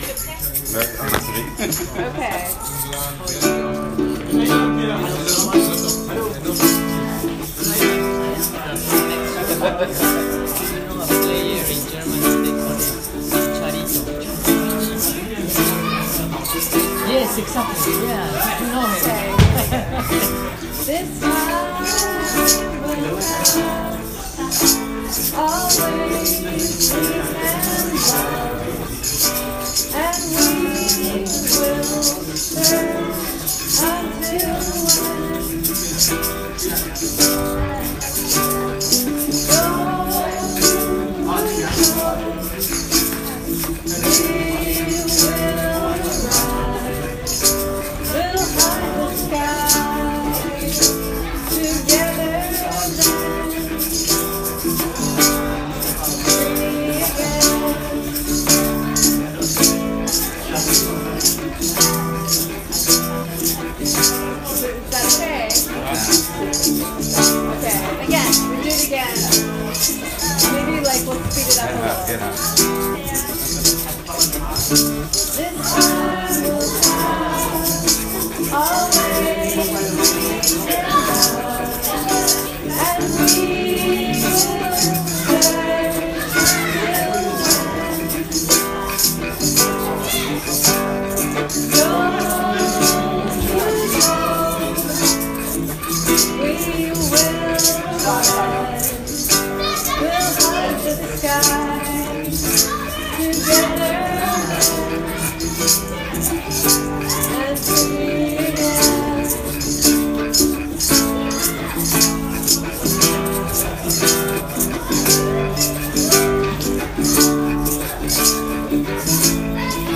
This was a day that many people joined in the poetry and songwriting process.
This song has not yet been finished. With two hours on an afternoon, we sometimes are only able to create the start of a song, a verse, or a chorus.